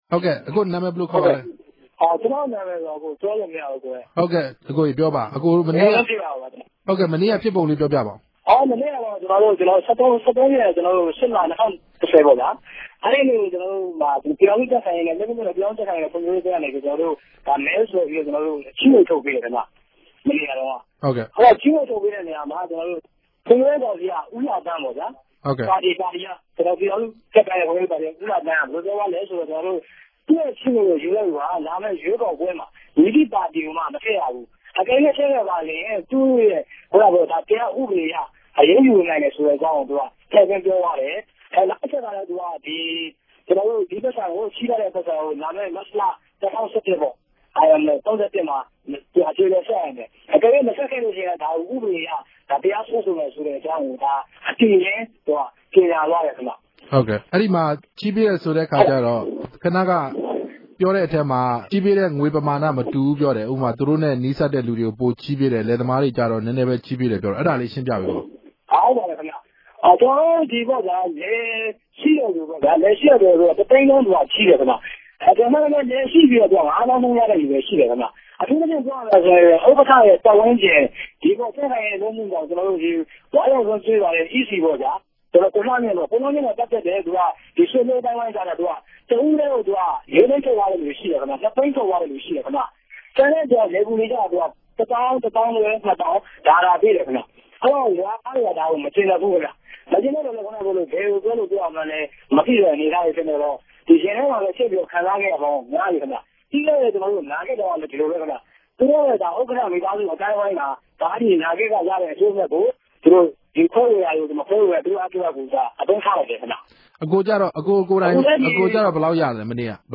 ဆက်သွယ်မေးမြန်းချက်။။